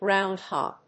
音節gróund・hòg 発音記号・読み方
/ˈgraʊˌndhɑg(米国英語), ˈgraʊˌndhɑ:g(英国英語)/